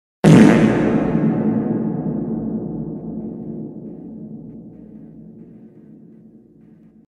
Reverb Fart Sound Effect Download: Instant Soundboard Button
Reverb Fart Sound Button - Free Download & Play